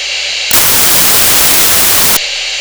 This unit has an electronic tone whistle which is a reasonable copy of a whistle.
The Whistle
mtc_whistle.wav